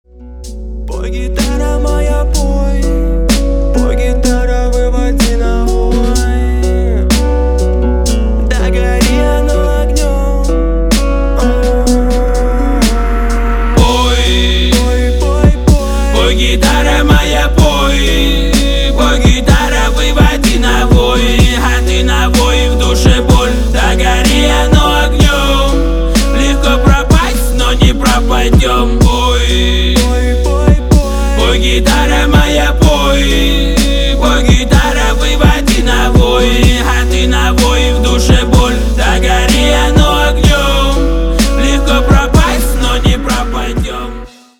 • Качество: 320, Stereo
лирика
грустные
русский рэп